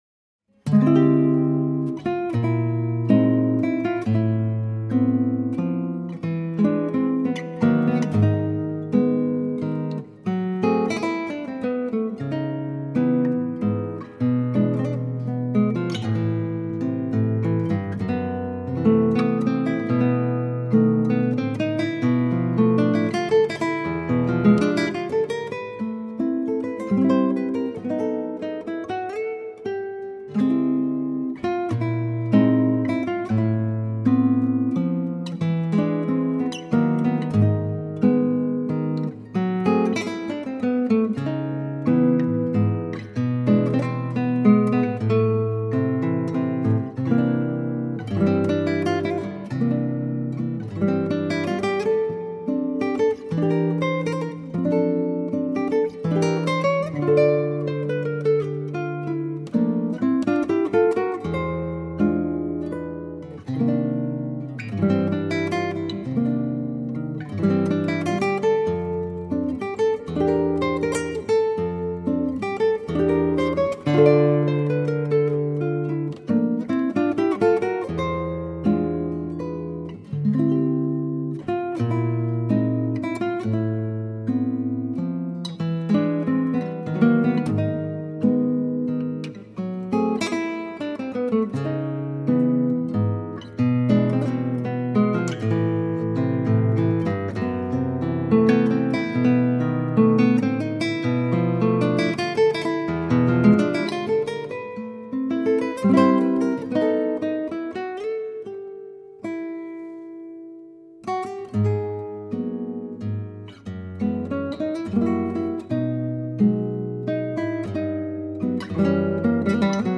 Instrumentação: violão solo
Tonalidade: Am | Gênero: instrumental brasileiro